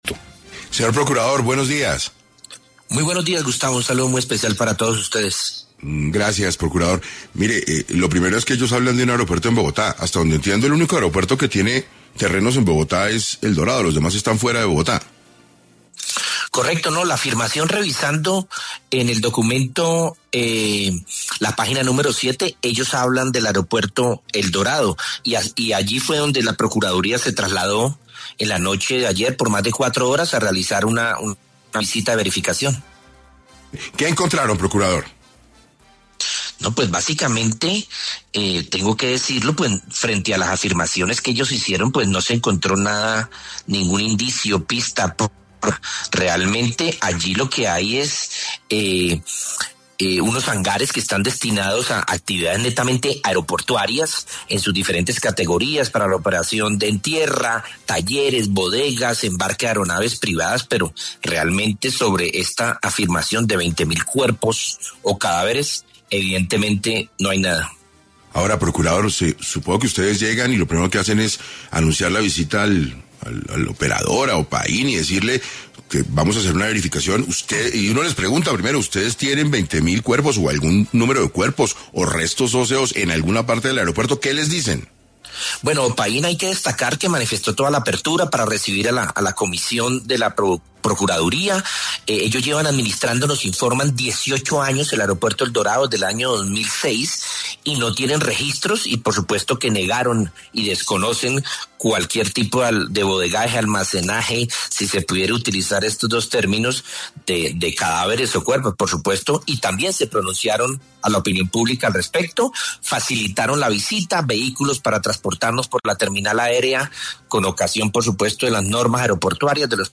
Este viernes 6 de diciembre, el procurador delegado para los Derechos Humanos, Javier Sarmiento, estuvo en entrevista con el noticiero ‘6 AM’ de Caracol Radio, en donde aclaró algunas inquietudes generadas tras la rueda de prensa de la ONU y la posterior visita de la Procuraduría al aeropuerto.